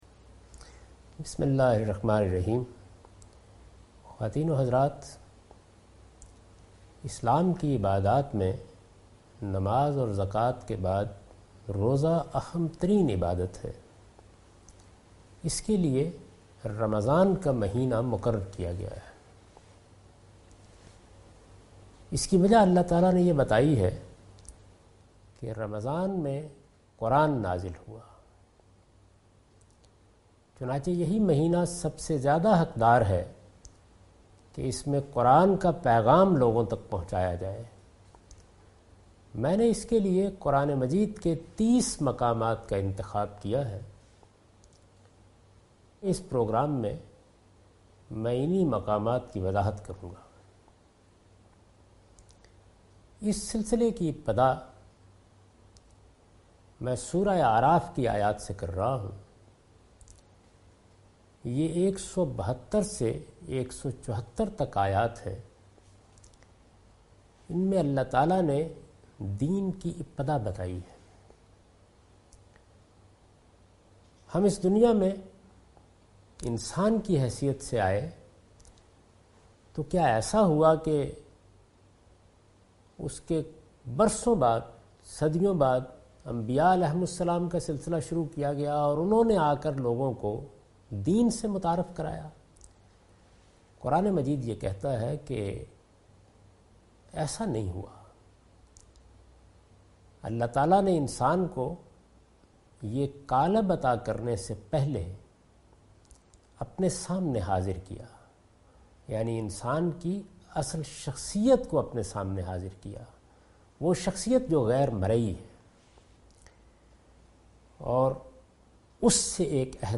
This series contains the lecture of Javed Ahmed Ghamidi delivered in Ramzan. He chose 30 different places from Quran to spread the message of Quran. From the help of verses of Surah Ara'f from 172-174 he try to explain the initiation of deen (religion-Islam).